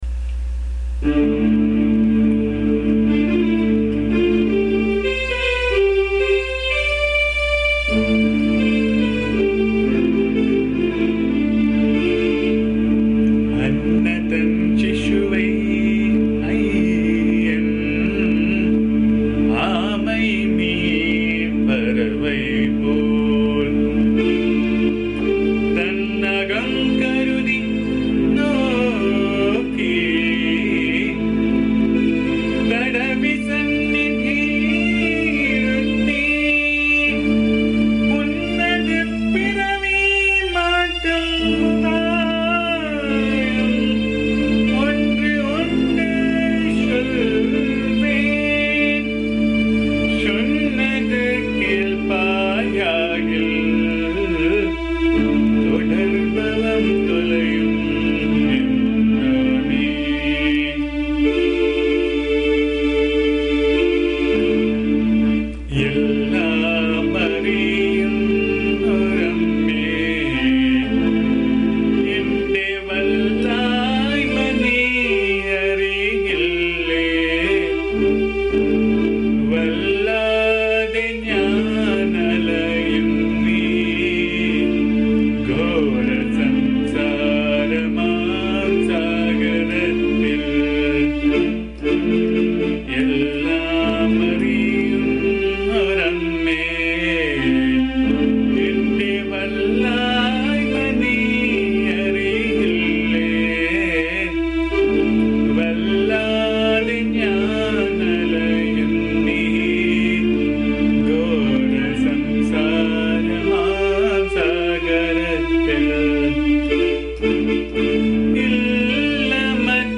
This is a very touching song with great lyrics and set in Reethigowla Raga. It is a slow song with a disciple-devotee praying to AMMA to get rid of his sorrows once and for all.
The song has been recorded in my voice which can be found here.
AMMA's bhajan song